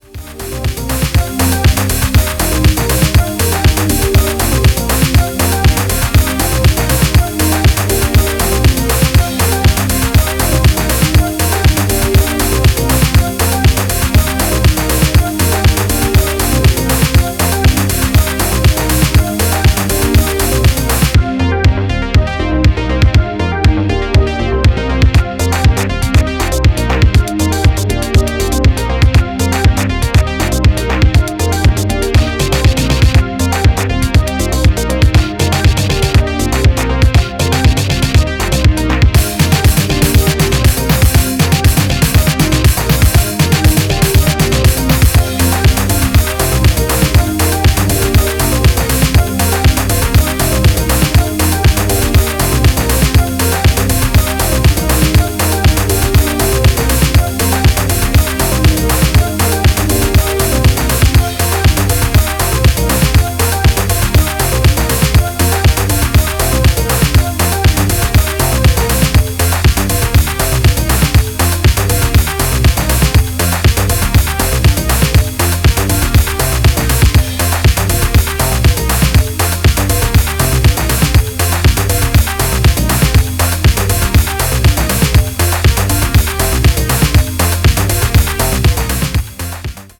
ビビッドなサウンドパレットが跳ねるシカゴ・アシッド